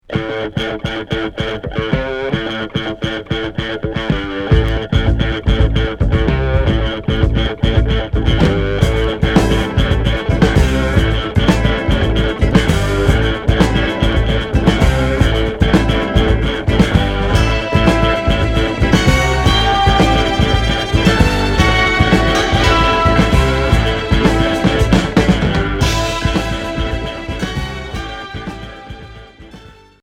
Pop rock Unique 45t ? retour à l'accueil